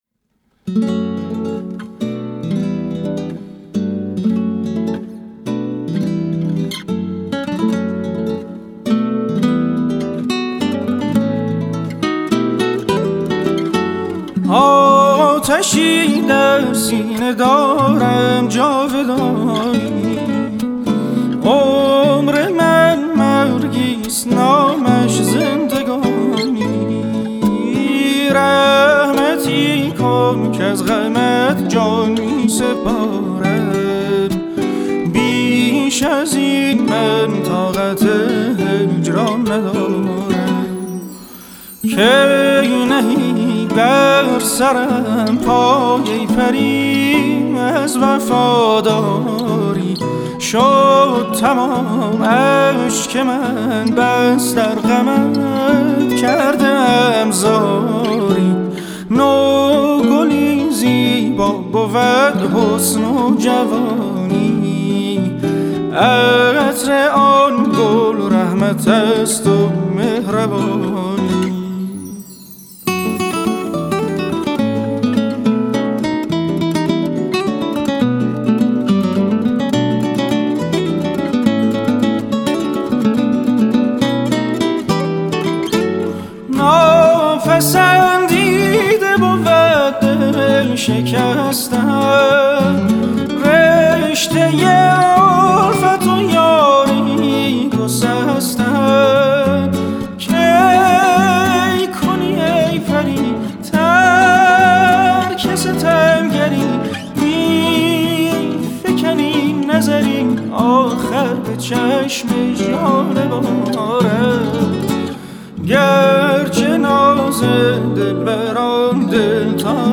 تصنیف قدیمی
گیتار
با بافتی جدید با اجرای گیتار